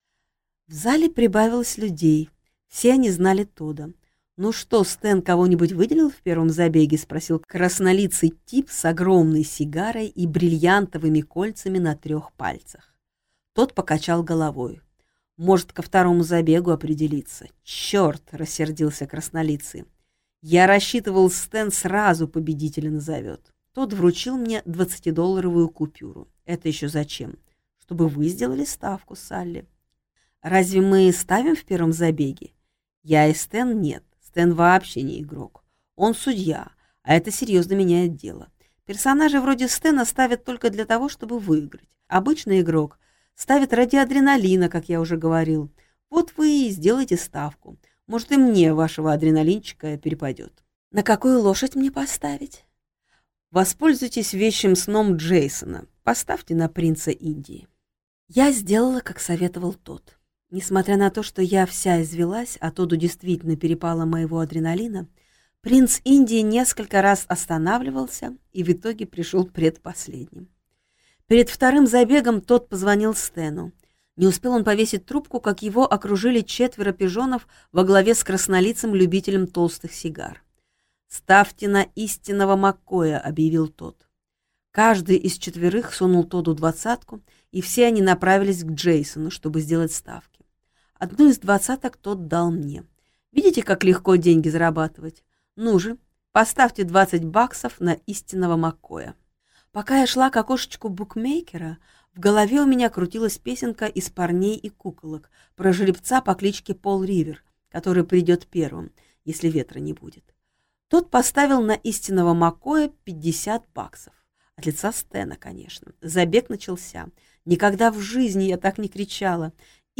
Аудиокнига Пятая Салли - купить, скачать и слушать онлайн | КнигоПоиск